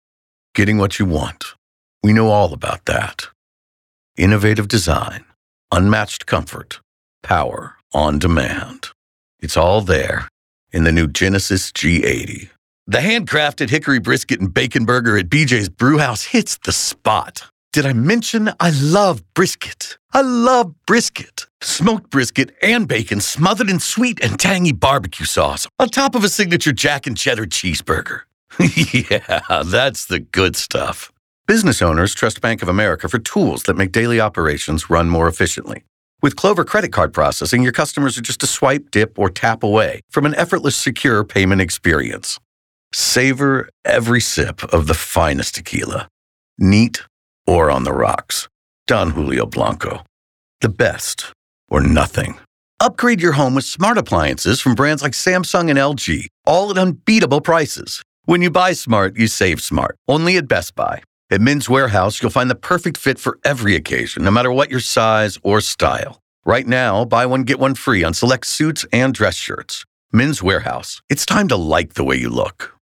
Male
Yng Adult (18-29), Adult (30-50)
Radio Commercials
Commercial Demo "Dry" No Music